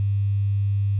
Grundschwingung 100Hz
APRecht100Hz.au